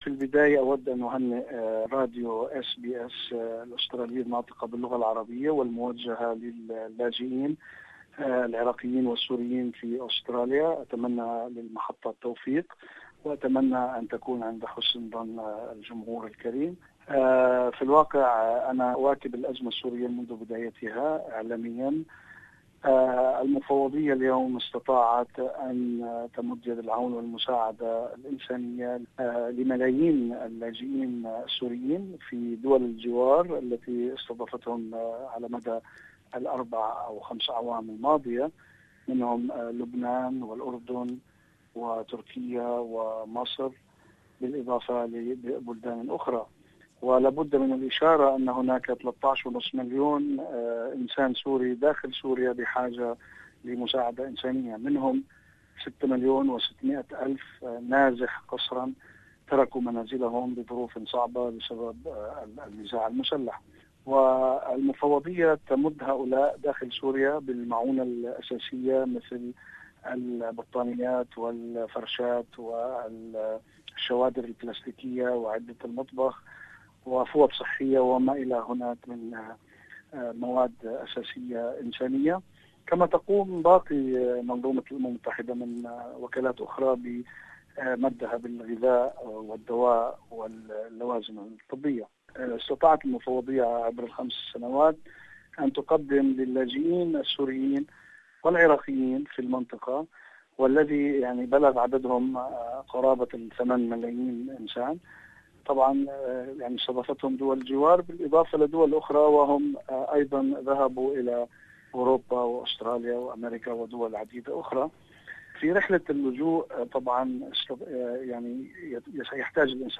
Balkans nation scraps policy that previously allowed in those from war-torn zones in Iraq and Syria. More about this issue, listen to this interview